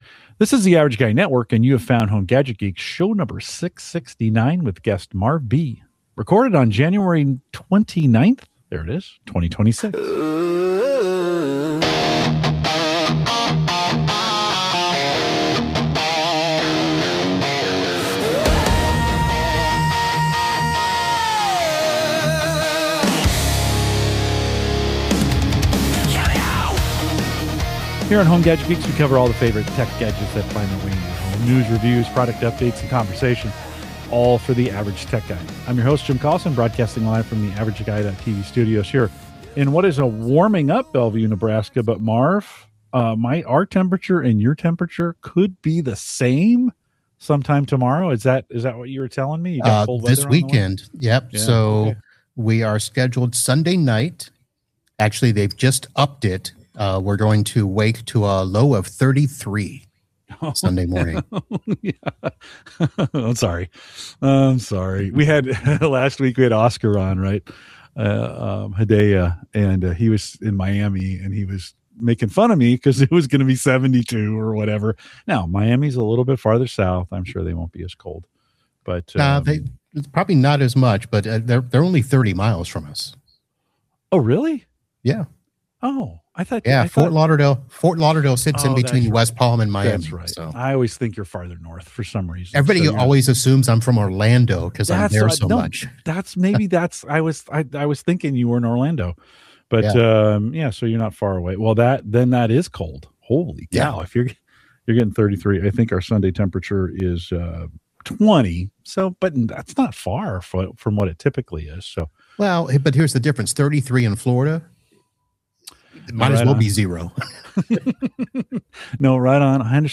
In this episode of Home Gadget Geeks, the conversation explores the real-world challenges of managing home technology as networks, devices, and expectations continue to grow. The discussion blends hands-on networking experience with broader reflections on tech habits, automation, and the pressure to constantly upgrade.